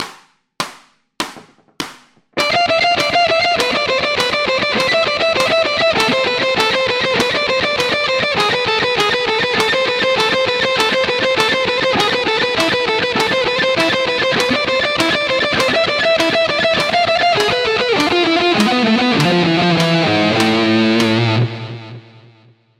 Следующее упражнение – классическая секвенция, в которой используется связка: указательный, средний/безымянный, мизинец, средний/безымянный. Звучит эта секвенция гораздо мелодичнее первых двух упражнений, поэтому и играть ее, как мне кажется, вам будет интереснее.
Аудио (100 УВМ)